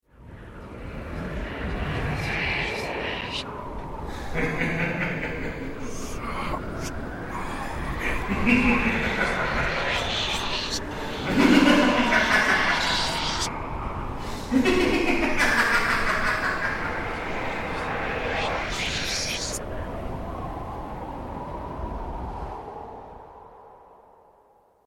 LaughingMan-Wind-Forwards.mp3